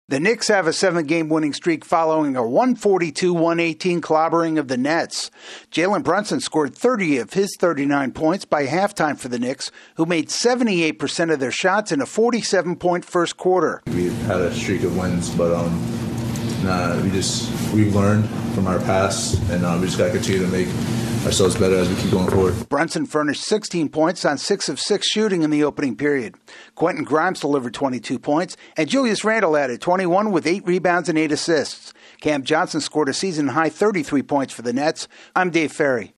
The Knicks blow out the Nets for the second straight meeting. AP correspondent